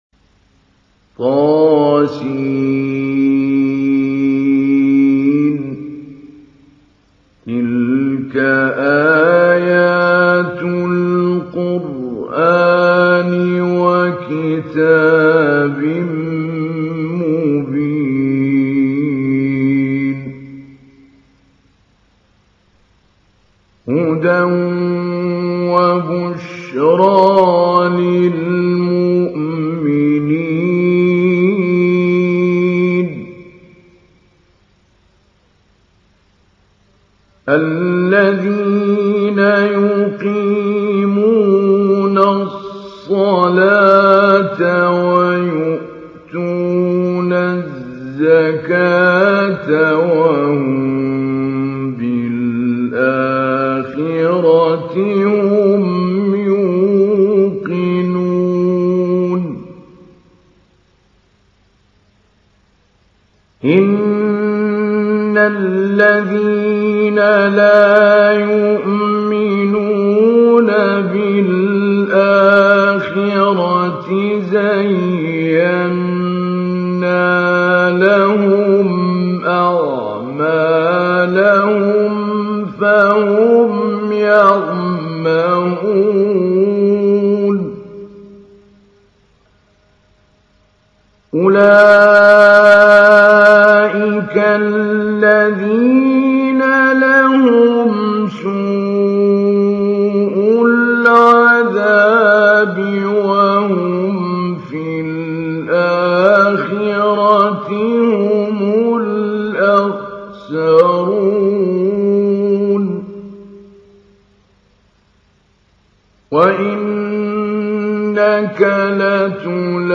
تحميل : 27. سورة النمل / القارئ محمود علي البنا / القرآن الكريم / موقع يا حسين